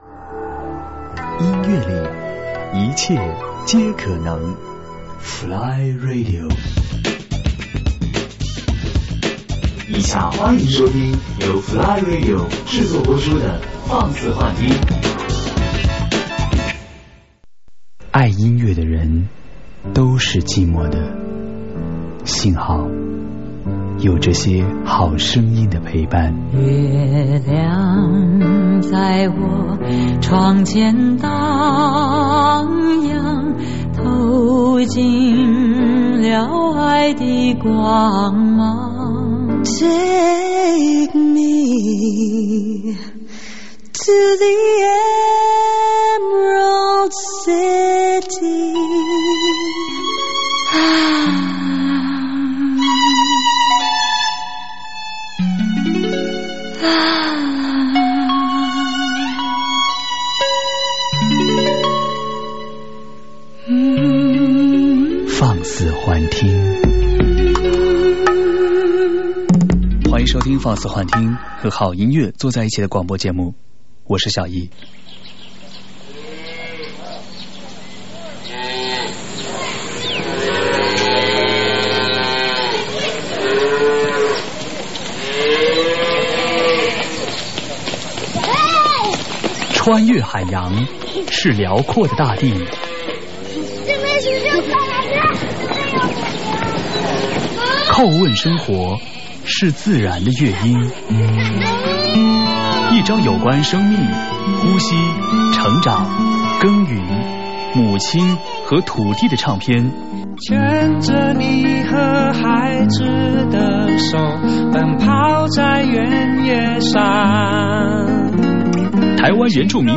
节目类型：音乐文化专栏